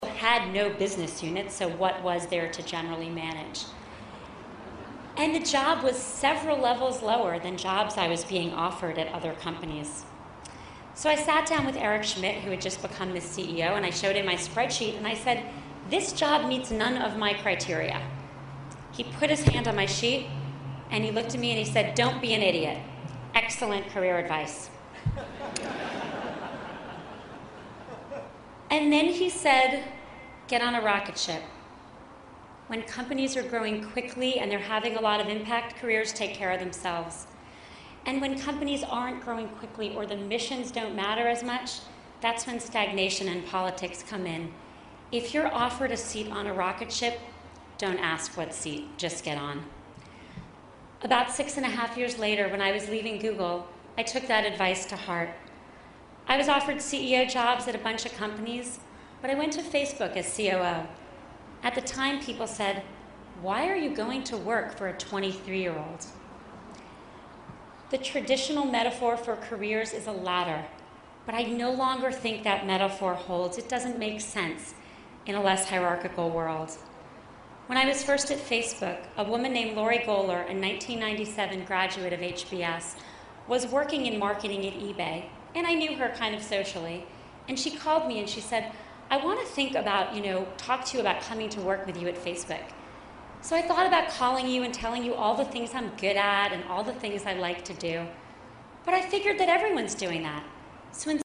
在线英语听力室公众人物毕业演讲第179期:桑德伯格2012哈佛商学院(4)的听力文件下载,《公众人物毕业演讲》精选中西方公众人物的英语演讲视频音频，奥巴马、克林顿、金庸、推特CEO等公众人物现身毕业演讲专区,与你畅谈人生。